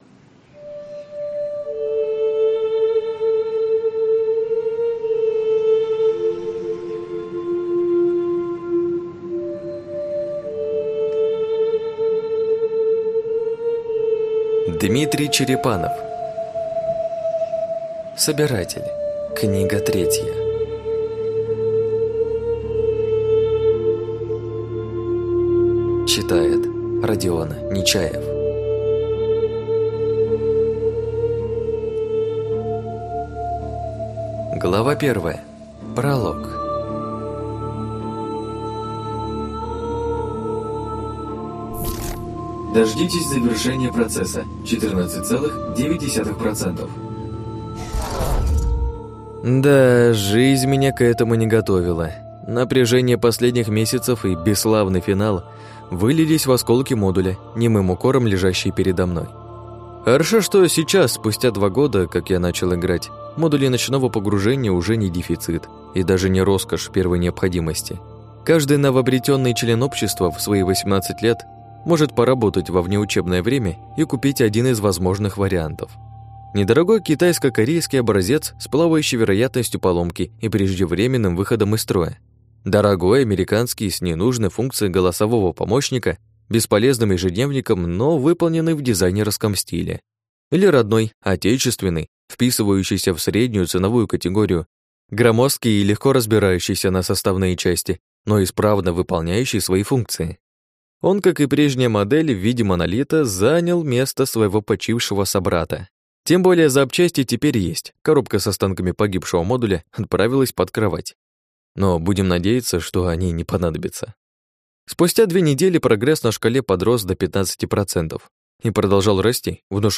Аудиокнига Собиратель. Книга 3 | Библиотека аудиокниг